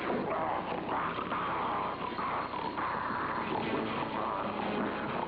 The snarling sounds he makes sound similar to the cartoon character!